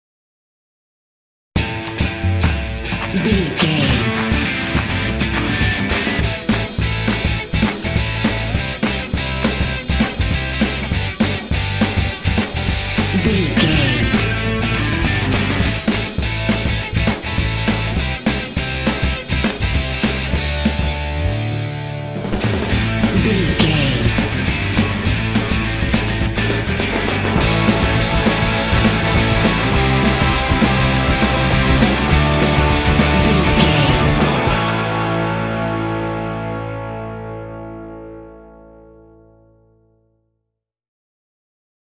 This cool rock track is suitable for lively sport games.
Aeolian/Minor
powerful
energetic
heavy
drums
bass guitar
electric guitar
rock
indie
alternative